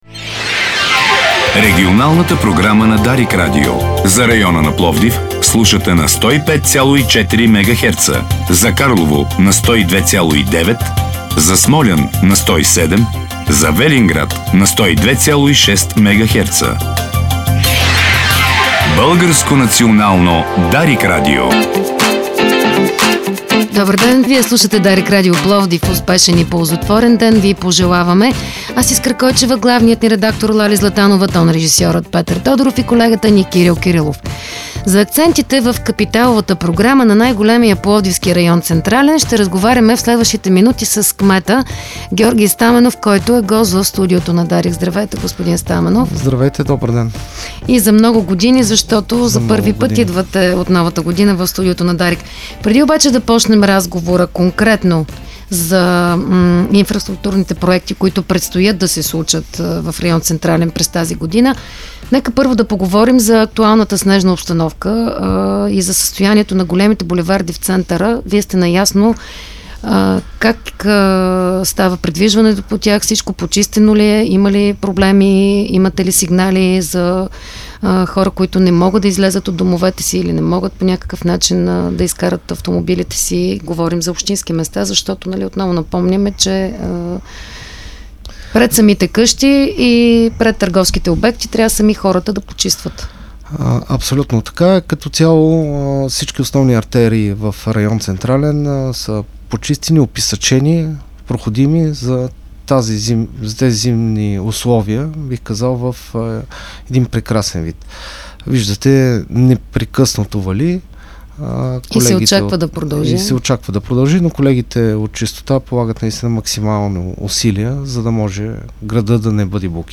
Проектна готовност за над 4 милиона и половина лева има администрацията на „Централен“, съобщи в студиото на Дарик радио кметът на най-големия пловдивски район Георги Стаменов.